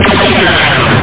weapons&explosions
1 channel
photon2.mp3